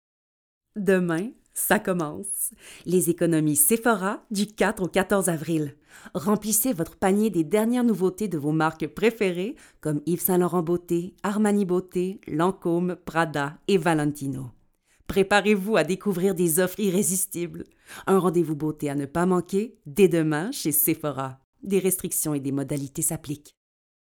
Démo de voix
Publicité Shephora - Démo Fictif